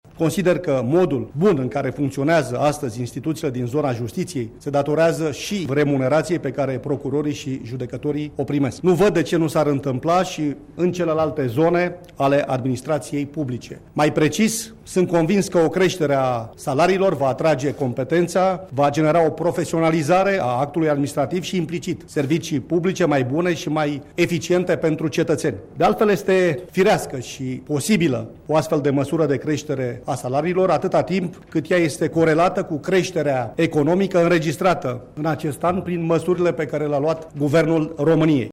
Premierul interimar, Gabriel Oprea, a afirmat, astăzi, că Executivul doreşte ca fiecare angajat la stat să primească un salariu corect în raport cu munca şi responsabilităţile sale :